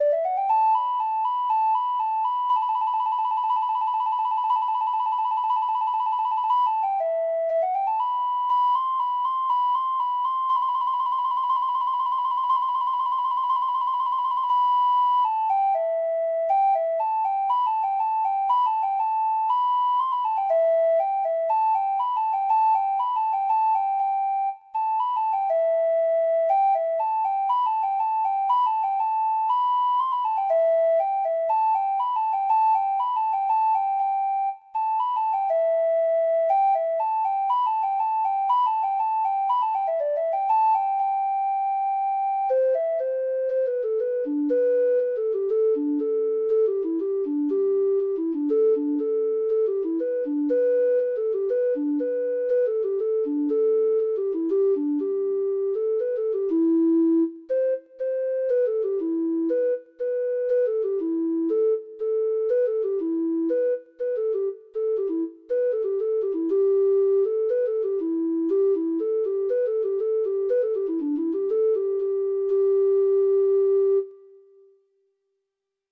Traditional Music of unknown author.
Traditional Sheet Music
Irish